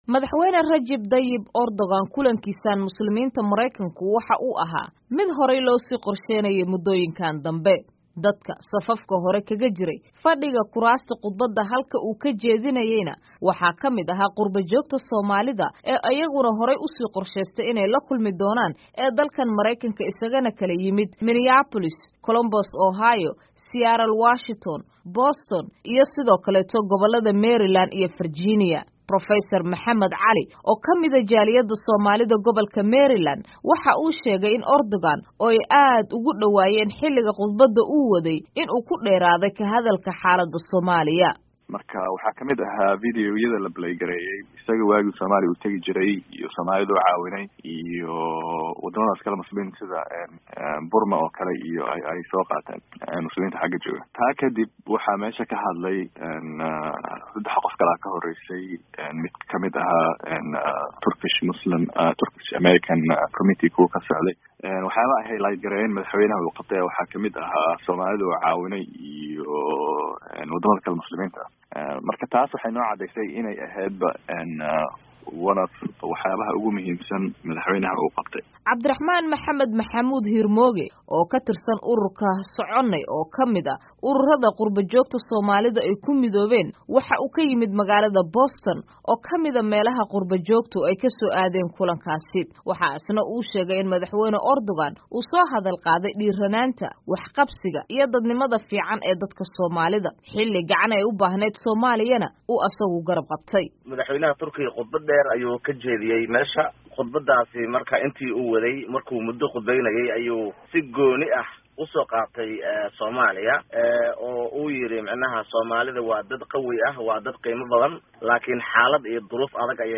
Khudbad uu jeediyay xalay markii uu Muslimiinta kula kulmay New York ayuu ku soo hadal qaaday xaaladda Soomaliya, qaxootiga Syria, la dagaalanka ISIS, iyo aayaha magaalada Qudus oo uu sheegay inuu difaaci doono.